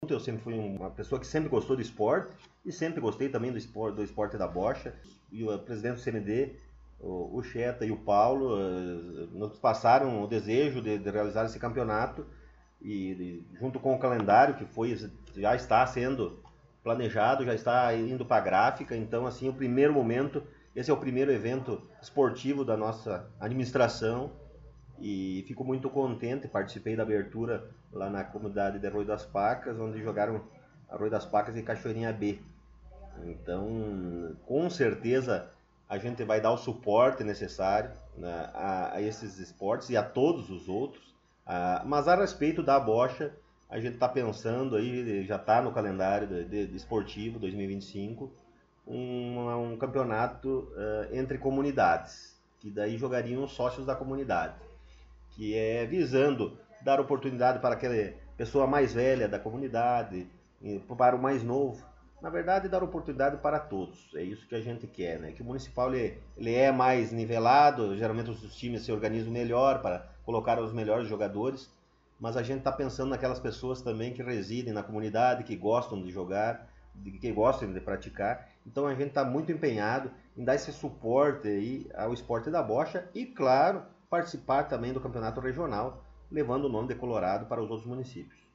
Após ida à Brasília o prefeito Rodrigo Sartori concedeu entrevista
Com a intuição de sabermos informações sobre a sua ida à Brasília e demais informações pertinentes a Administração Municipal, nós conversamos com ele na última sexta-feira no Gabinete da Prefeitura Municipal.